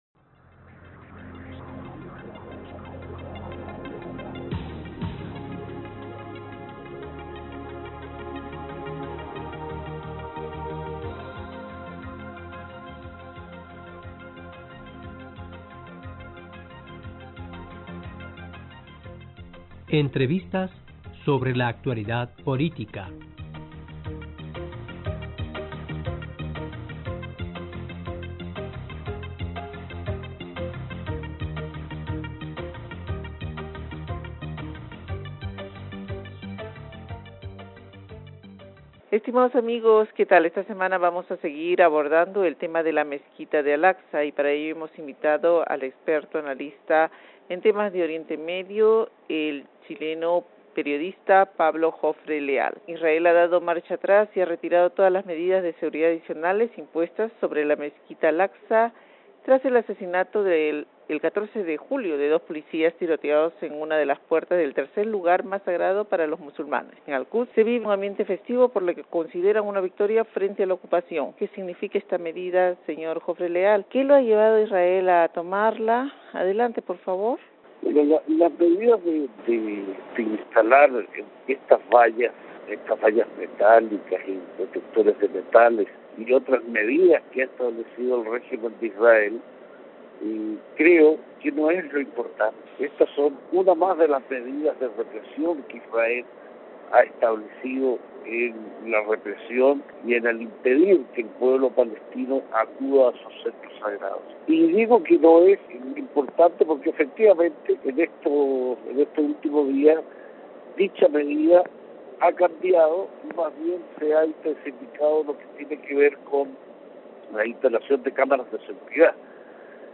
Esta semana vamos a seguir abordando el tema de la Mezquita Al-Aqsa y para ello hemos invitado al experto analista en temas de Oriente Medio